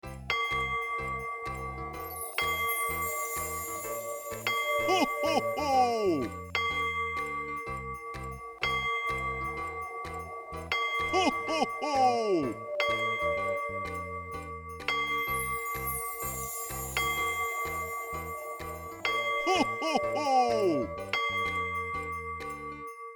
cuckoo-clock-11.wav